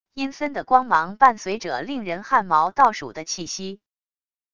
阴森的光芒伴随者令人汗毛倒数的气息wav音频